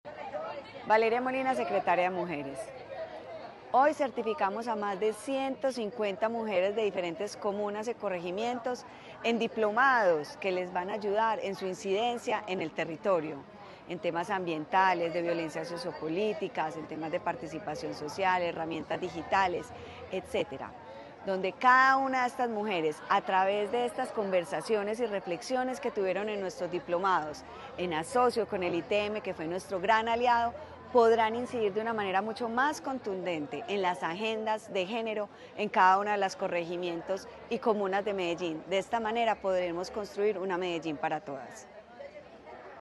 Palabras de Valeria Molina, secretaria de las Mujeres La Escuela de Igualdad de Género celebró la certificación de 159 mujeres, entre ellas líderes comunitarias, afrodescendientes e indígenas.